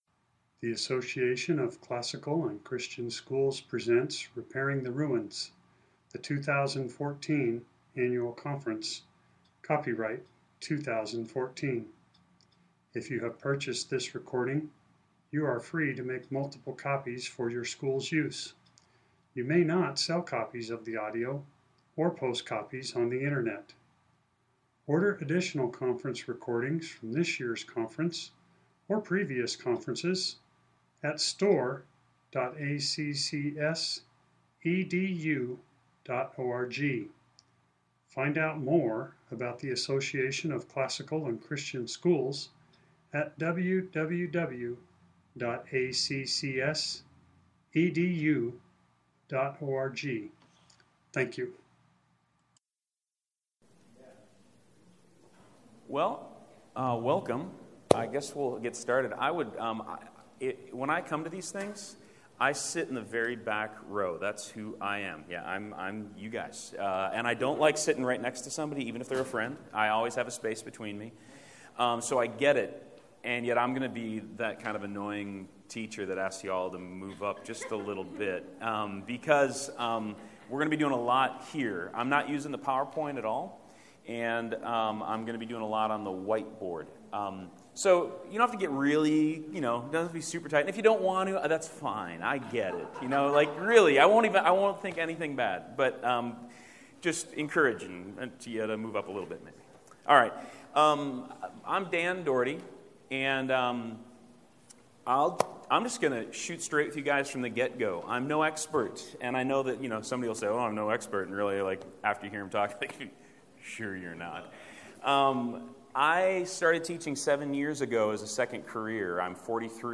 2014 Workshop Talk | 1:07:31 | All Grade Levels, Virtue, Character, Discipline
Jan 19, 2019 | All Grade Levels, Conference Talks, Library, Media_Audio, Virtue, Character, Discipline, Workshop Talk | 0 comments
The Association of Classical & Christian Schools presents Repairing the Ruins, the ACCS annual conference, copyright ACCS.